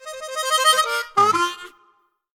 Country_Blues.ogg